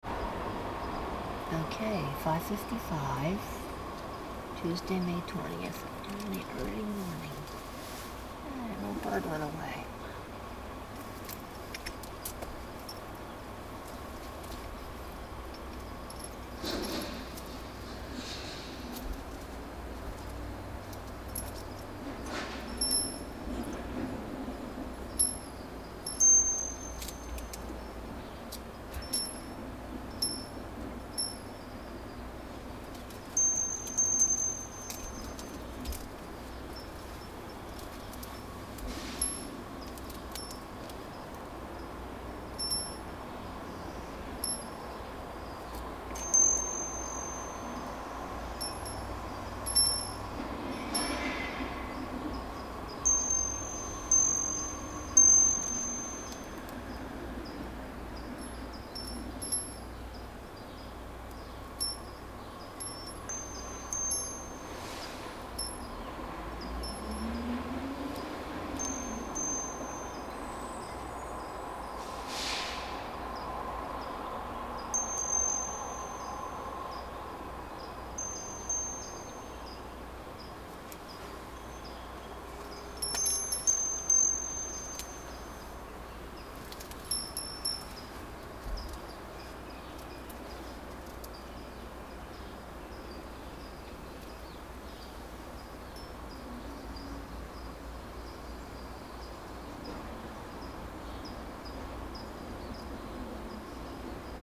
As soon as I went outside to record, he took off and so we are left with the sound of the garbage trucks and street cleaners. And the one streetcar making its way to the beach.